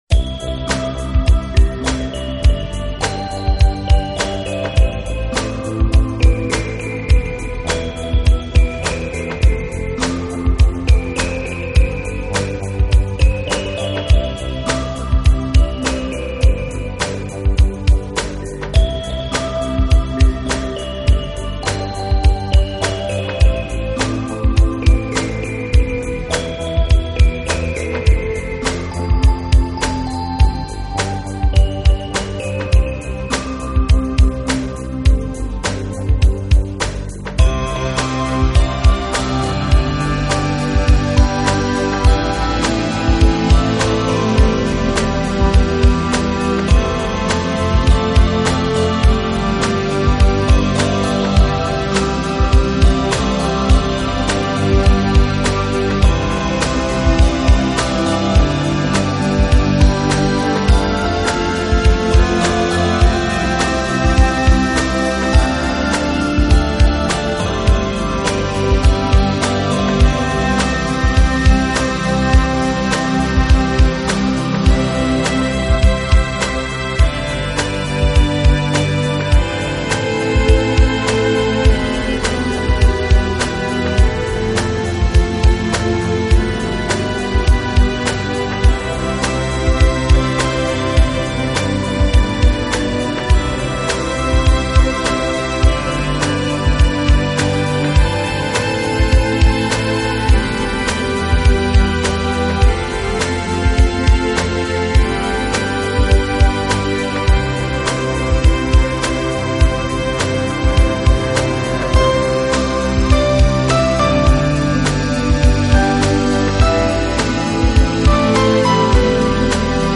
【新世纪钢琴】
整部作品柔美、轻松、梦幻。